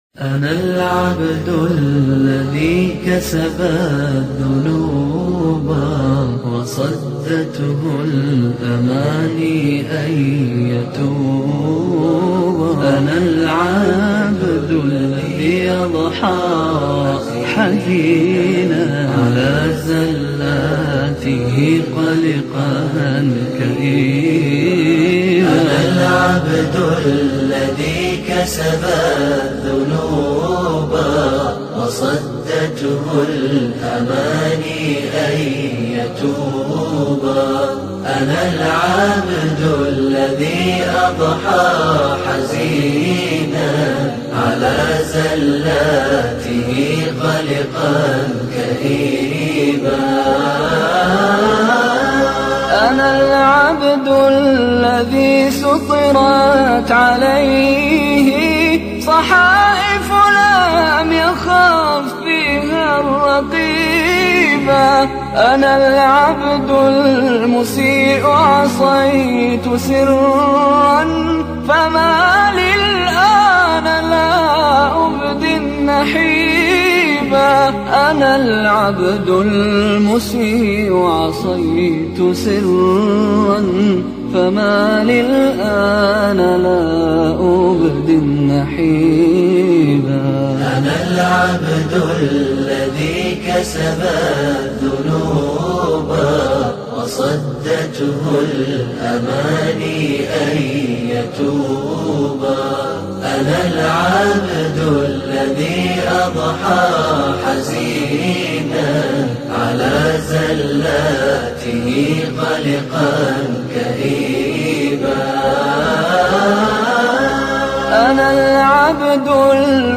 Nasheed
NASHEED BY MISHARY AL AFASY